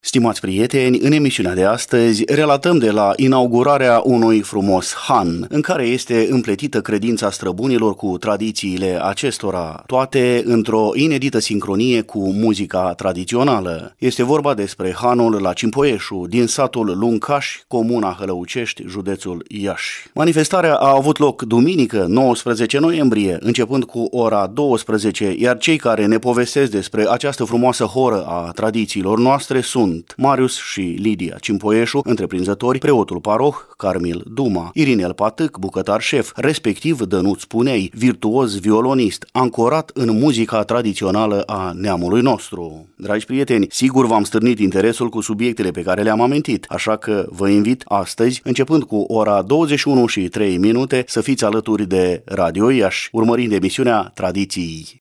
Stimați prieteni, în emisiunea de astăzi, relatăm de la inaugurarea unui frumos han, în care este împletită credința străbunilor cu tradițiile acestora, toate într-o inedită sincronie cu muzica tradițională.